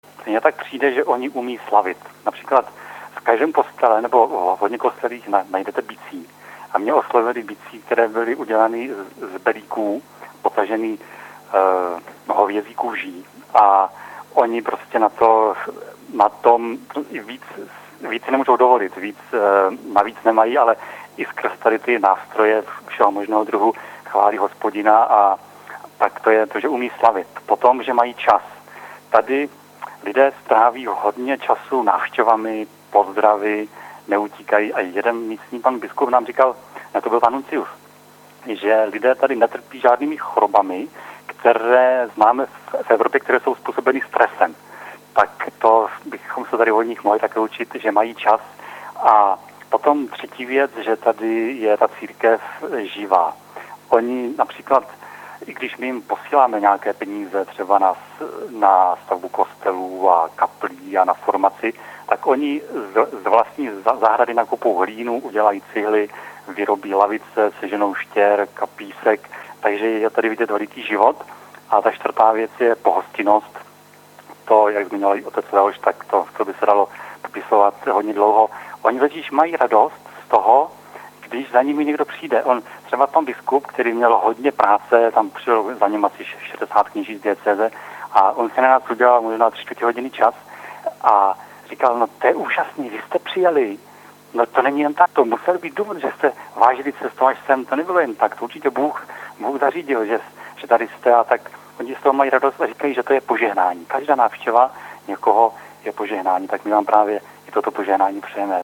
Posluchačům Proglasu se podruhé telefonicky přihlásili o Květné neděli před 14. hod. zdejšího i zambijského času.